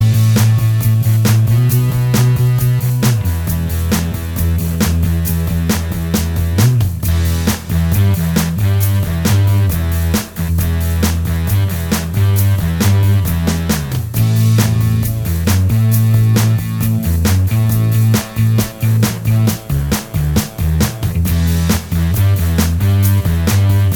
Minus Guitars Glam Rock 3:46 Buy £1.50